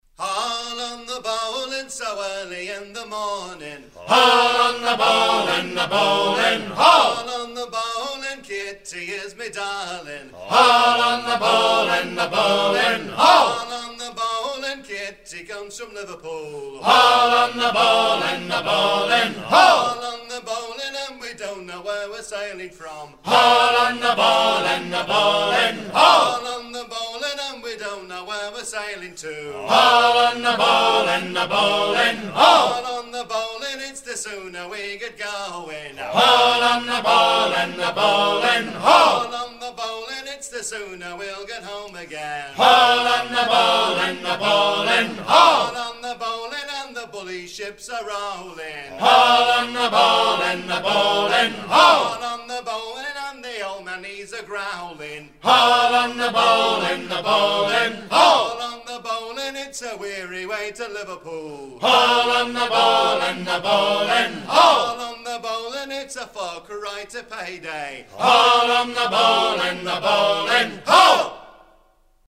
gestuel : à hisser à grands coups
circonstance : maritimes
Pièce musicale éditée